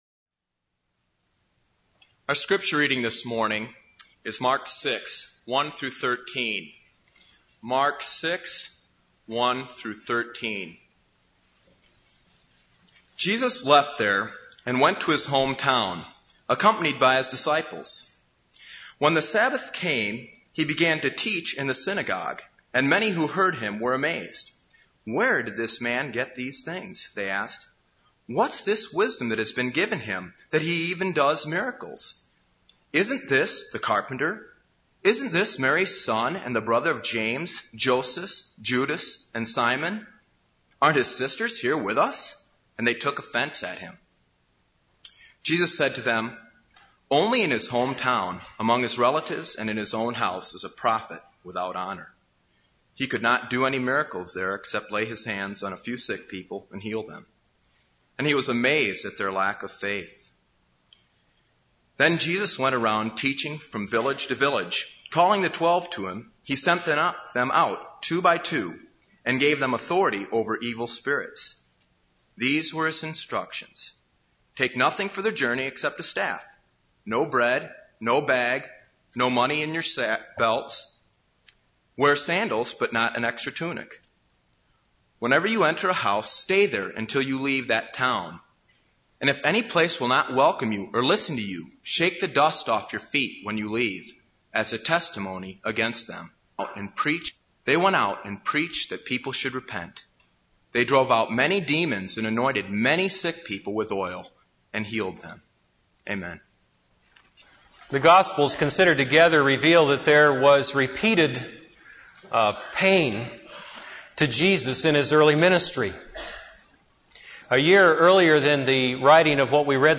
This is a sermon on Mark 6:1-13.